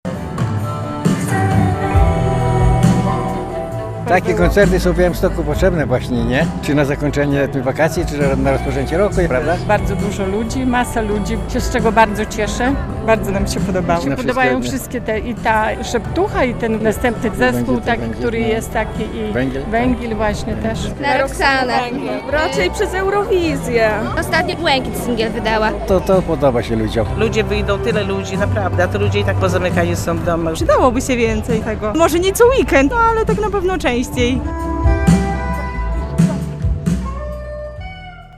Drugi dzień imprezy "Białystok pełen muzyki" - relacja